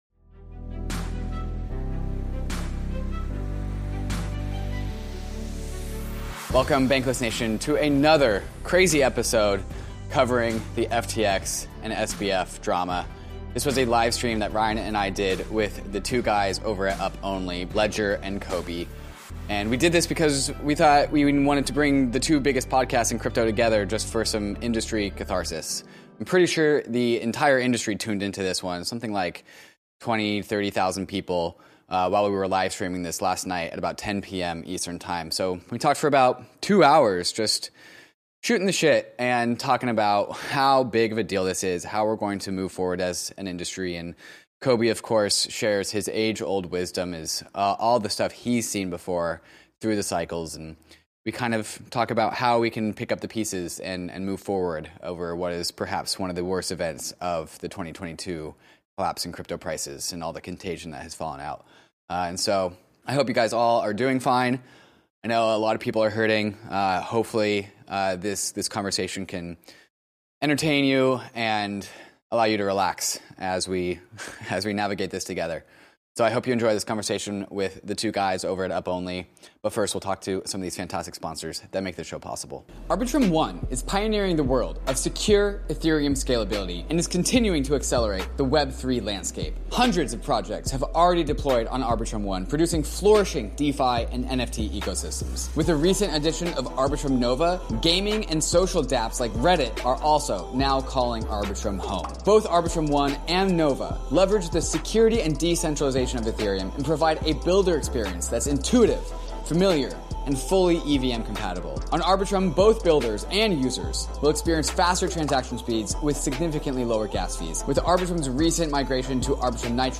With a mix of banter and serious insights, they provide tips for thriving in these challenging times. 02:18:23 forum Ask episode play_arrow Play